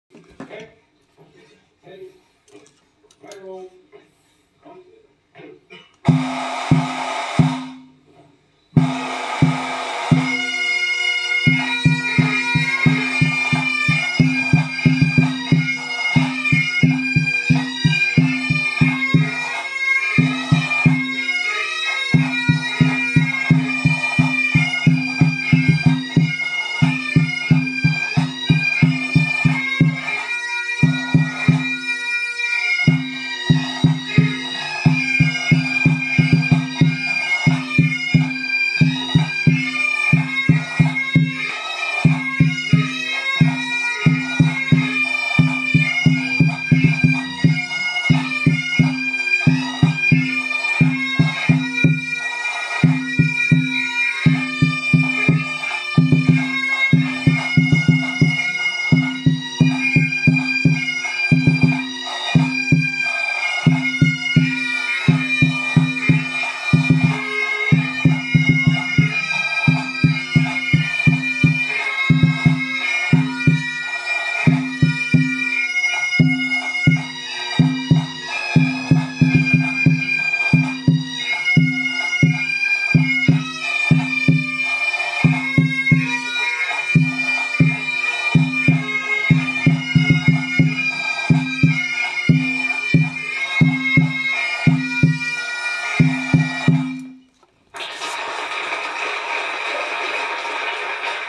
Tenor Drum